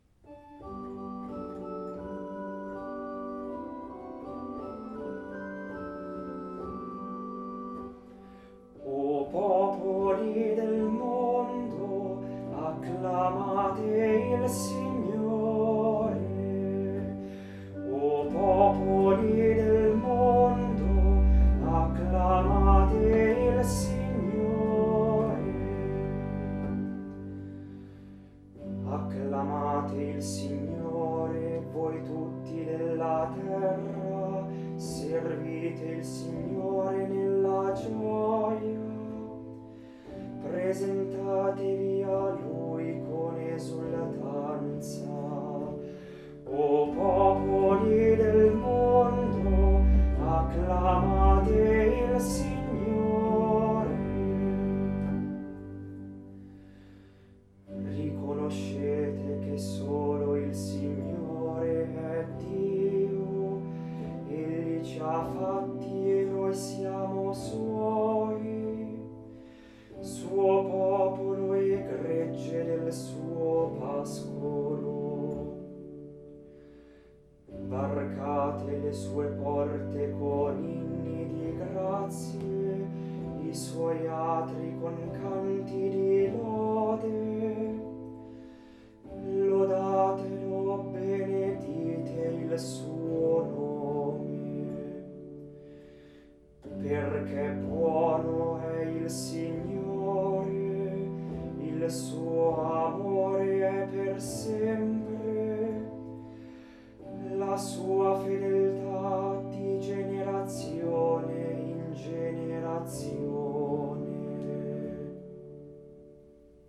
Audio esecuzione a cura degli animatori musicali del Duomo di Milano